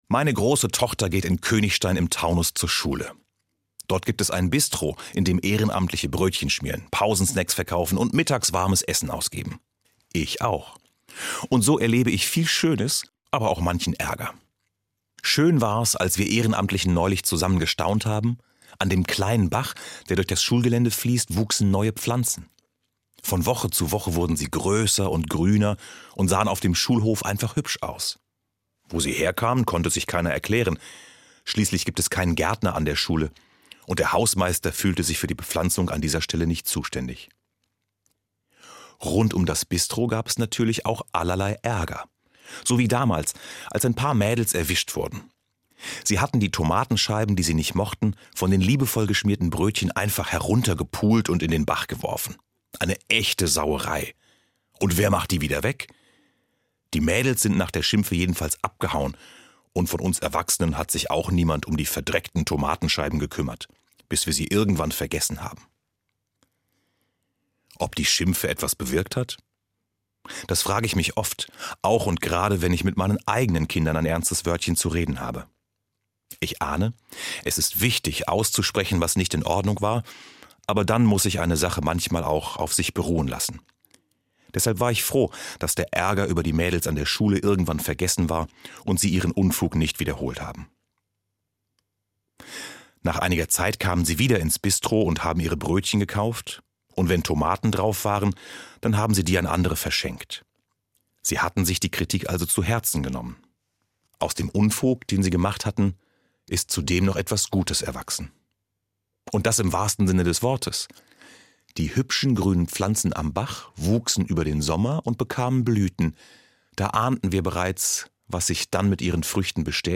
Eine Sendung von